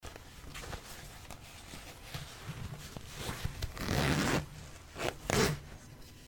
Звуки штанов